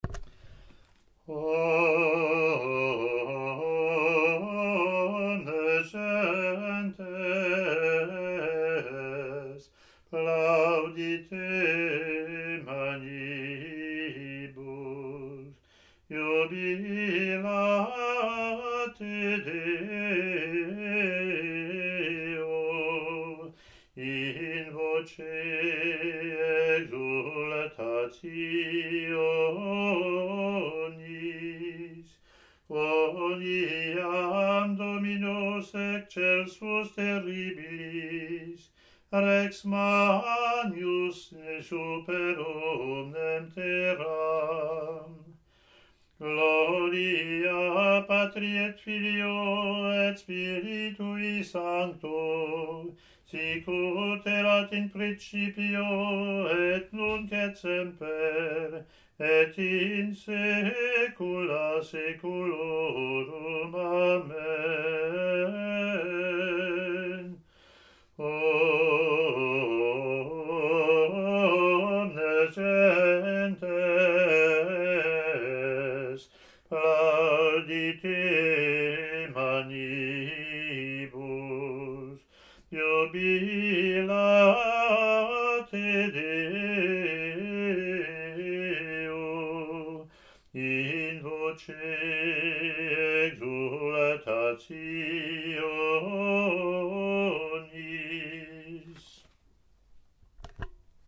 Introit in Solfeggio:
ot13-introit-gm.mp3